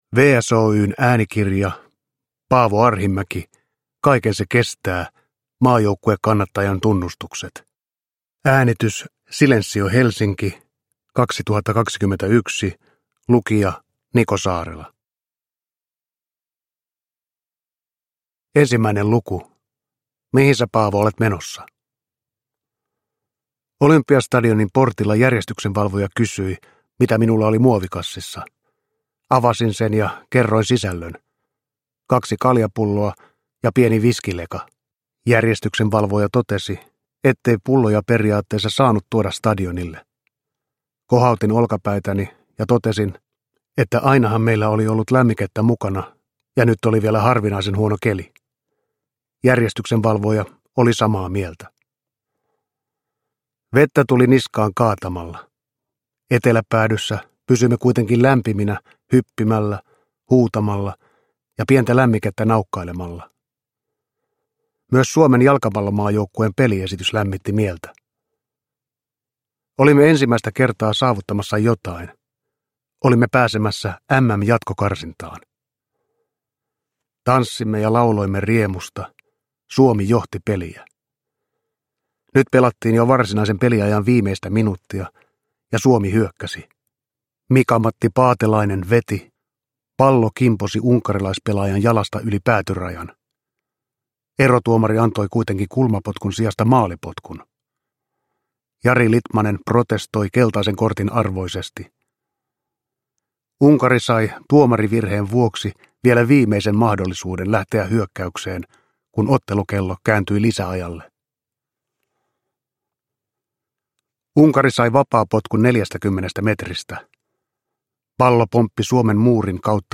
Kaiken se kestää – Ljudbok – Laddas ner